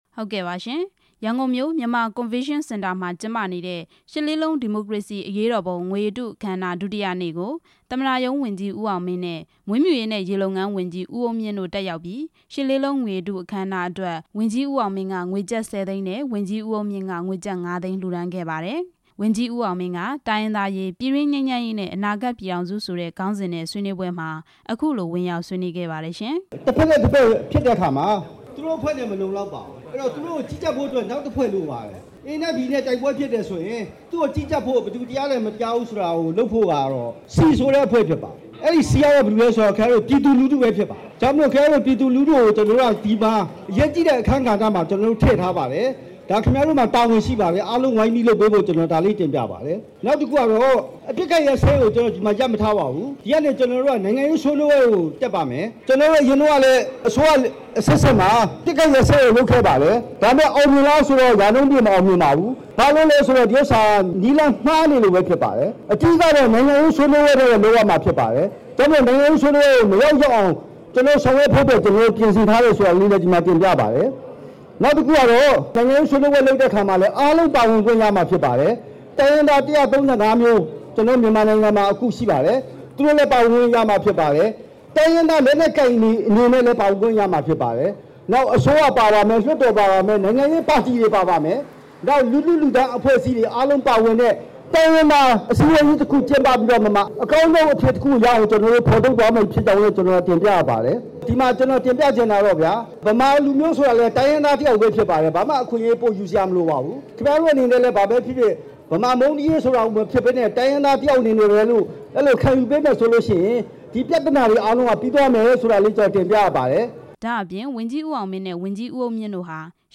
ရှစ်လေးလုံး ငွေရတုအခမ်းအနား ဒုတိယနေ့ တင်ပြချက်